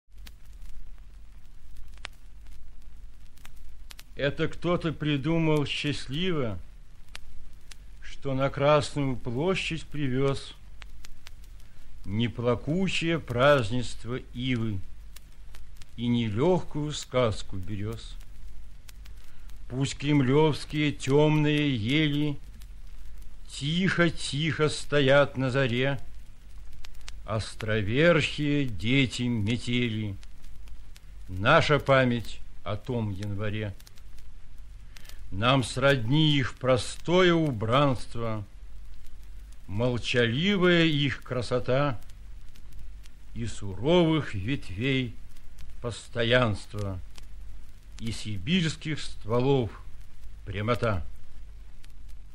1. «Ярослав Смеляков – Кремлевские ели (читает автор)» /